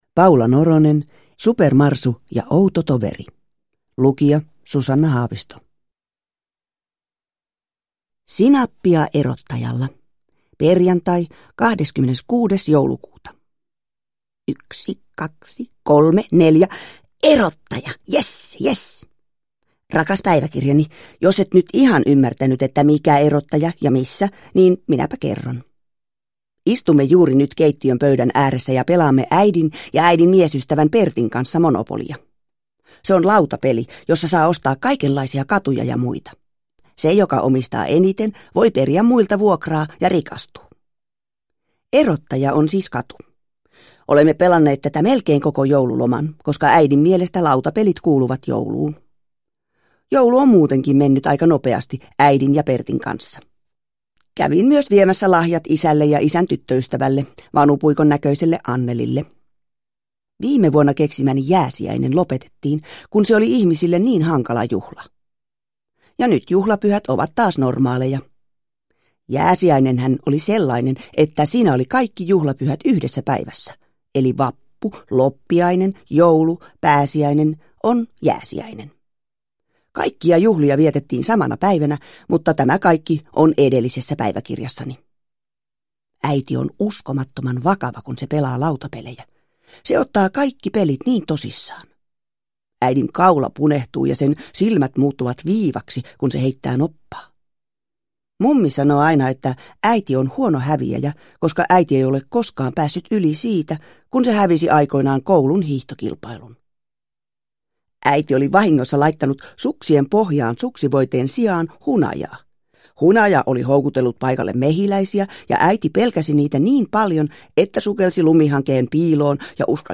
Supermarsu ja outo toveri – Ljudbok – Laddas ner
Uppläsare: Susanna Haavisto